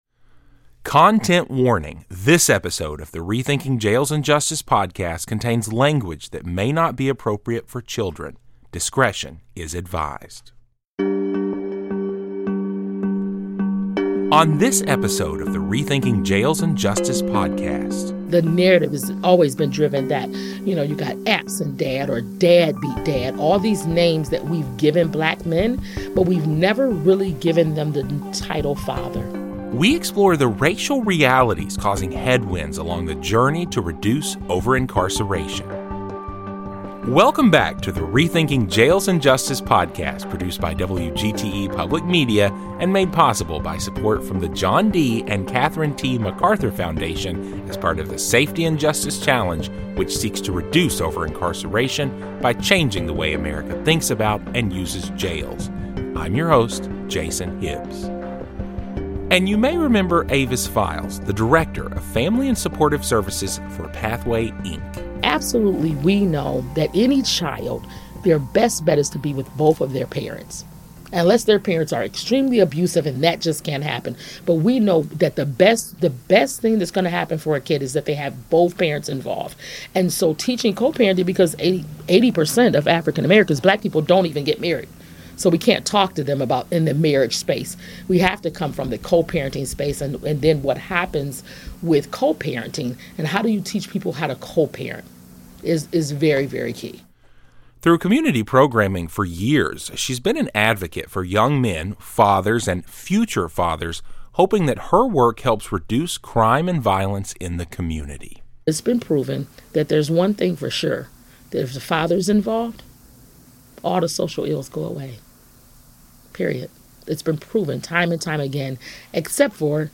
Community leaders explore the harsh racial realities that complicate efforts to reduce over-incarceration. A long-time advocate for young men and fathers speaks about the challenges of co-parenting, systemic racism, and the deep-rooted distrust between communities of color and the criminal justice system.